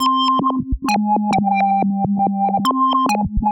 Index of /musicradar/uk-garage-samples/136bpm Lines n Loops/Synths